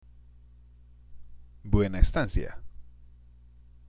＜発音と日本語＞
（ブエナ　エスタンシア）